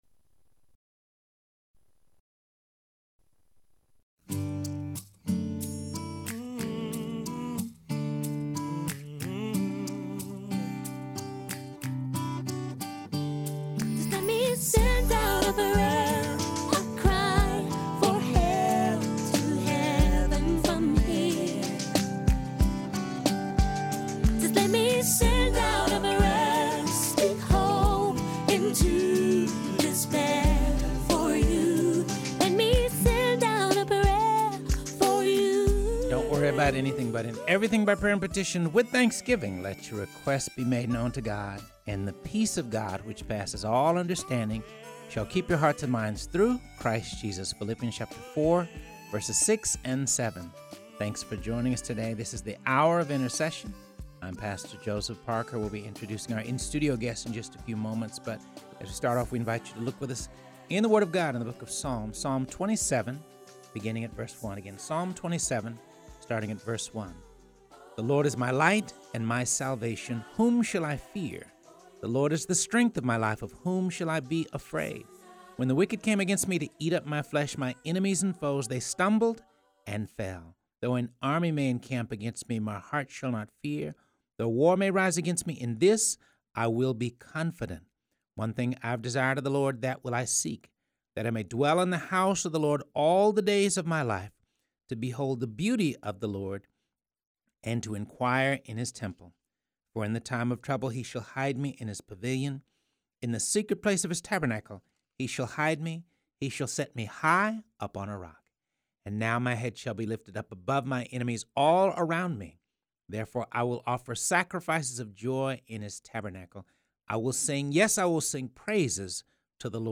in studio to talk about the work of the clinic.